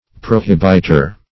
Meaning of prohibiter. prohibiter synonyms, pronunciation, spelling and more from Free Dictionary.
Search Result for " prohibiter" : The Collaborative International Dictionary of English v.0.48: Prohibiter \Pro*hib"it*er\, n. One who prohibits or forbids; a forbidder; an interdicter.